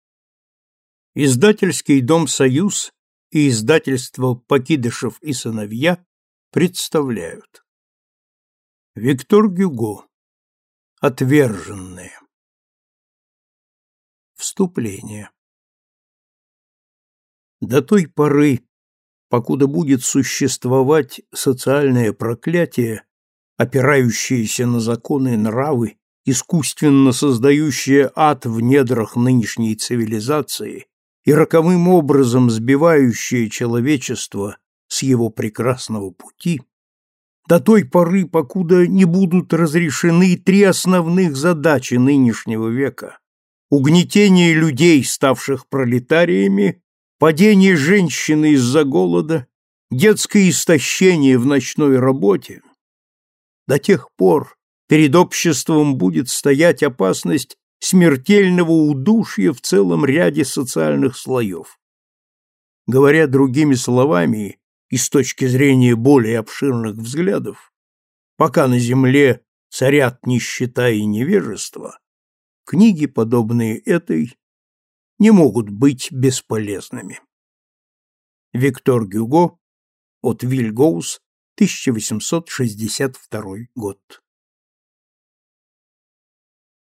Аудиокнига Отверженные (Издается с сокращениями) | Библиотека аудиокниг